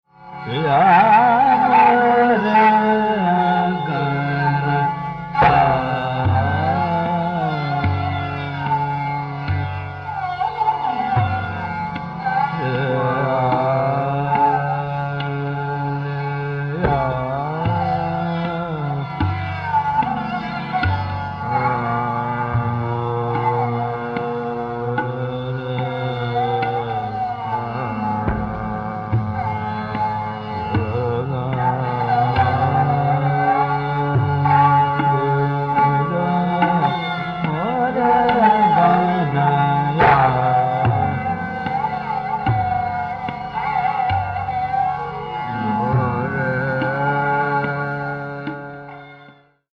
• Tanpura: Sa–Pa